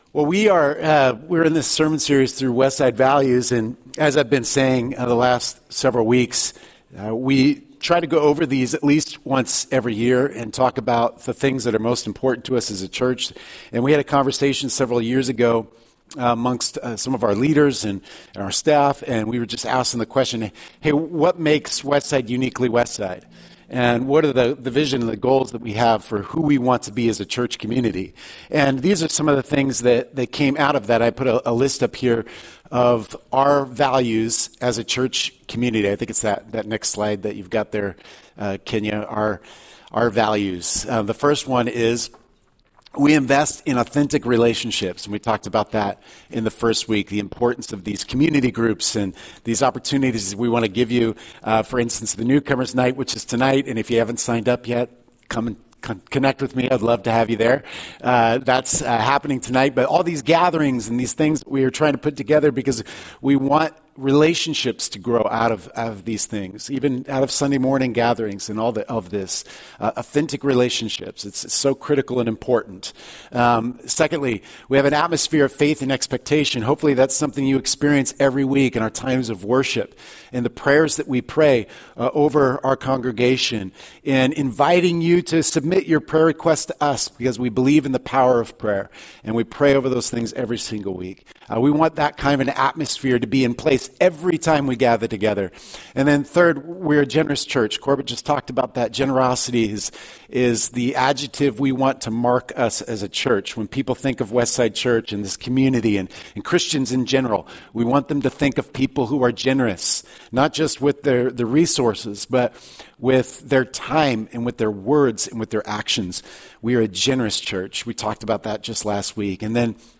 A few years ago, we set out to put words to those passions and we compiled a list of our core values. During this sermon series, we will be sharing them with you!